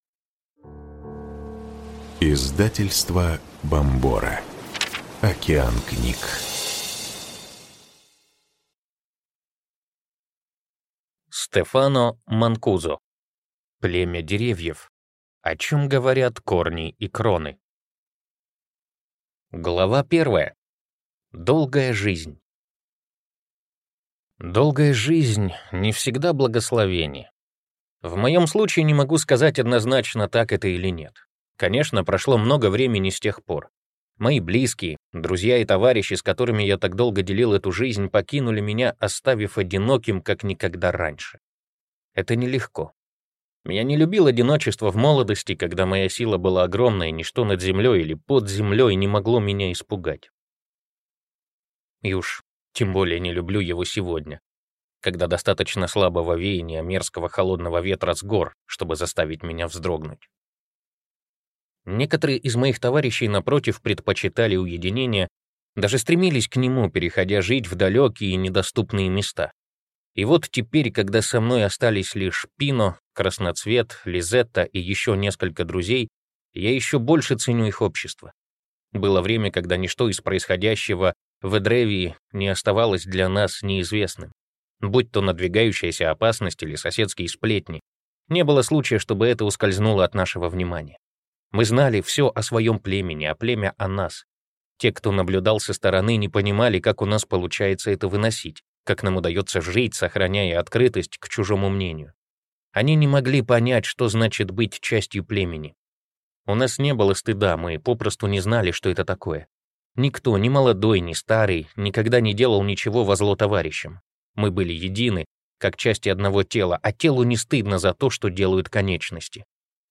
Ты такой светлый (слушать аудиокнигу бесплатно) - автор Туре Ренберг